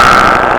Sky Phase 7 Horror Sound Button | Sound Effect Pro
Instant meme sound effect perfect for videos, streams, and sharing with friends.